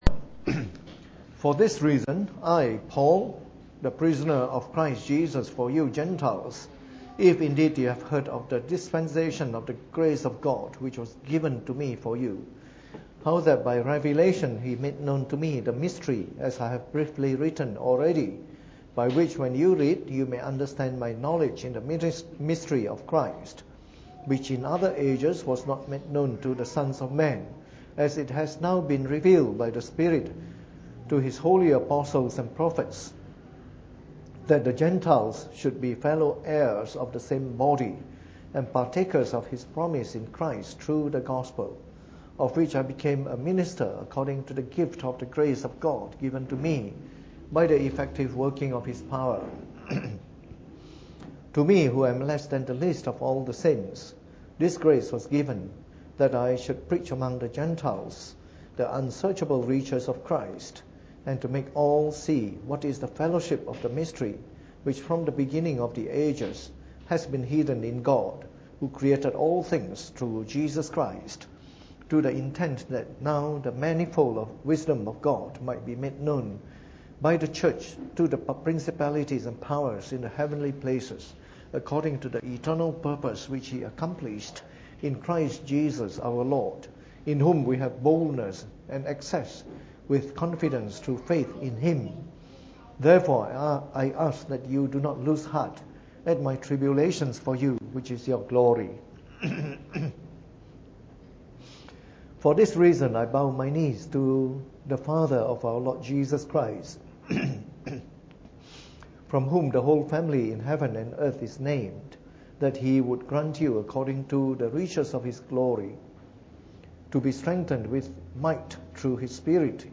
Preached on the 15th of February 2017 during the Bible Study, from our series on the Apostles’ Creed.